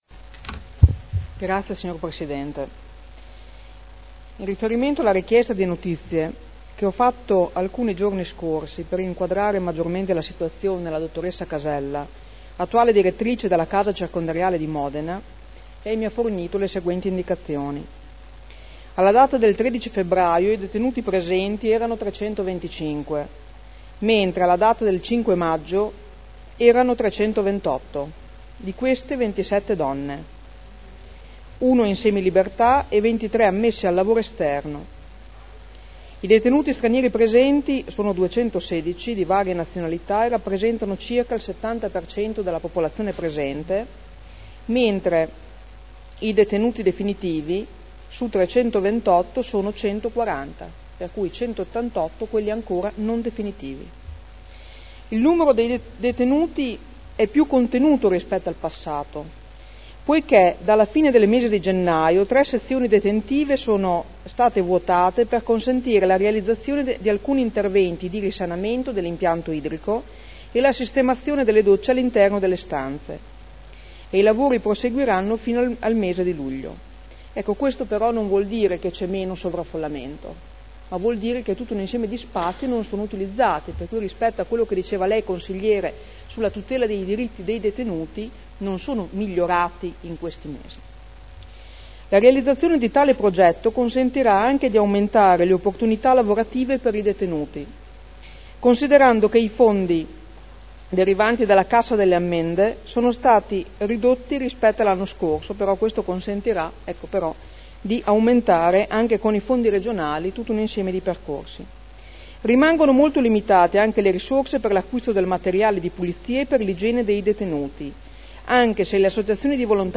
Francesca Maletti — Sito Audio Consiglio Comunale